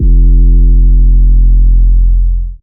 DDW2 808 5.wav